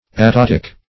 Search Result for " aptotic" : The Collaborative International Dictionary of English v.0.48: Aptotic \Ap*tot"ic\, a. Pertaining to, or characterized by, aptotes; uninflected; as, aptotic languages.